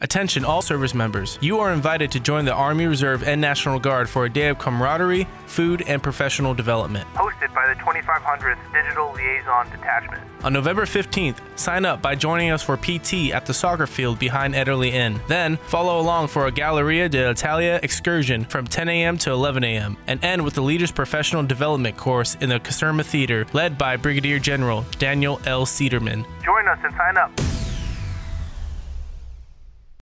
This is a radio spot for the Digital Liaison Detachment hosting an event that includes PT, Museum walkthrough, and a Leaders Professional Development Course led by Brigadier General Daniel L. Cederman.